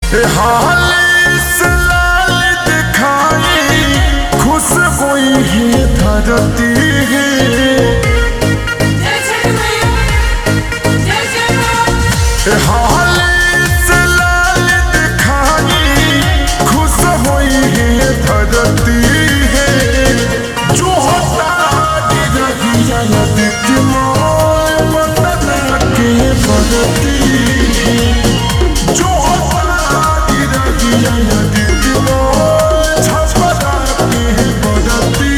Bhojpuri Songs
Slow Reverb Version
• Simple and Lofi sound
• High-quality audio
• Crisp and clear sound